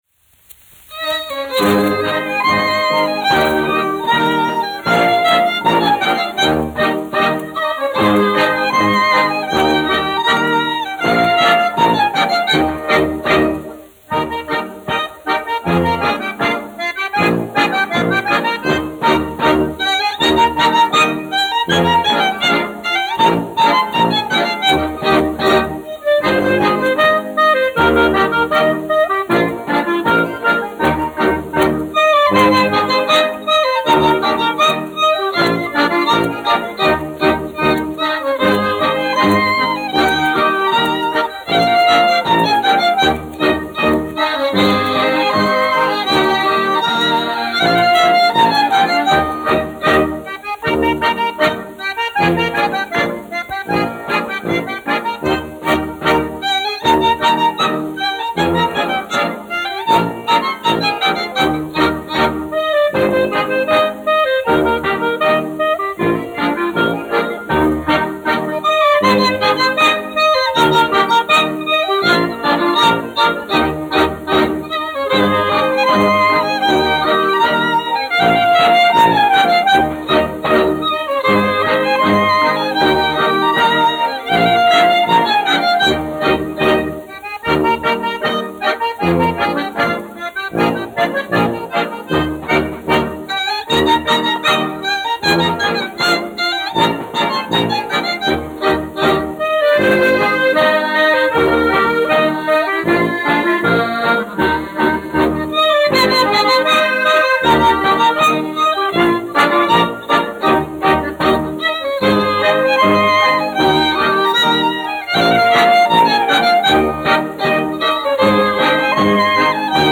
1 skpl. : analogs, 78 apgr/min, mono ; 25 cm
Sarīkojumu dejas
Latvijas vēsturiskie šellaka skaņuplašu ieraksti (Kolekcija)